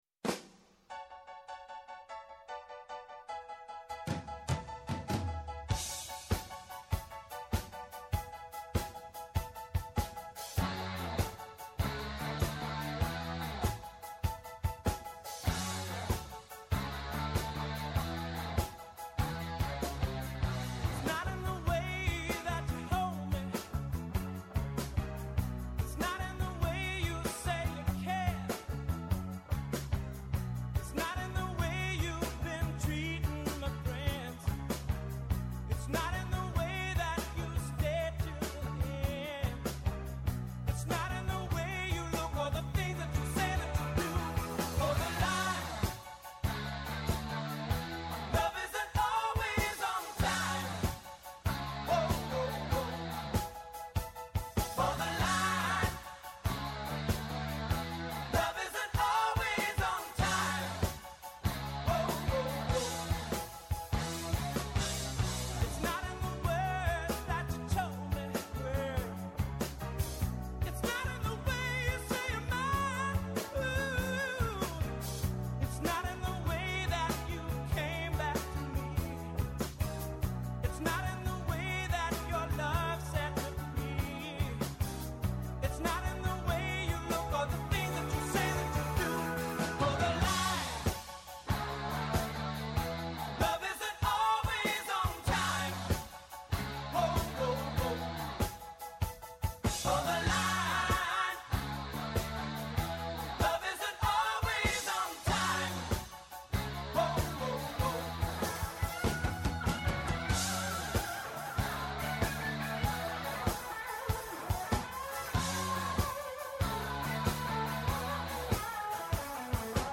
Συμφωνούν, διαφωνούν, φωτίζουν και αποκρυπτογραφούν τα γεγονότα με πολύ κέφι, πολλή και καλή μουσική και πολλές εκπλήξεις. Με ζωντανά ρεπορτάζ από όλη την Ελλάδα, με συνεντεύξεις με τους πρωταγωνιστές της επικαιρότητας, με ειδήσεις από το παρασκήνιο, πιάνουν τιμόνι στην πρώτη γραμμή της επικαιρότητας.